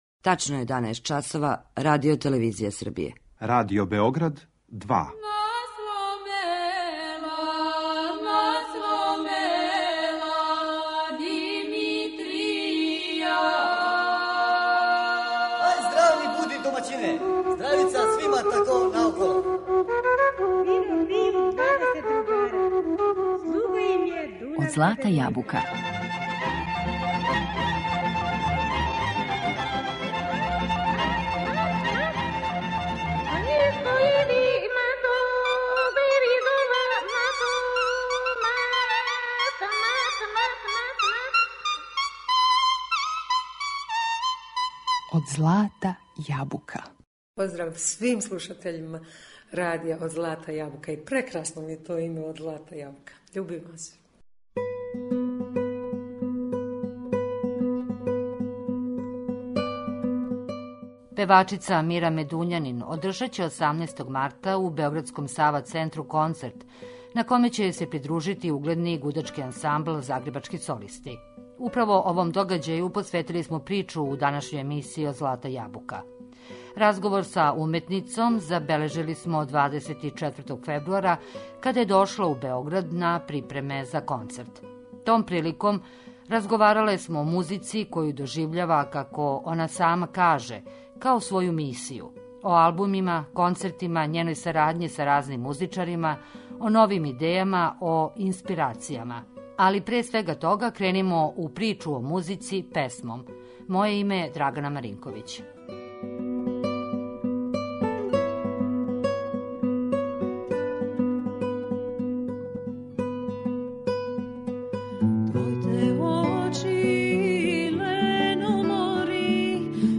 Гошћа је Амира Медуњанин